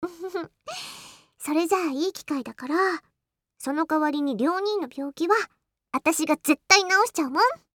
サンプルボイス1